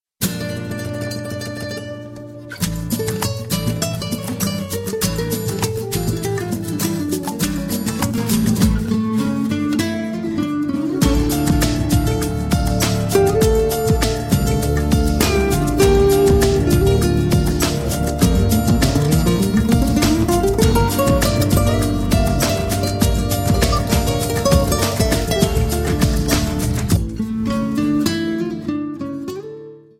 Dzwonki na telefon Gitara Solo Instrumentalne
Kategorie Instrumentalny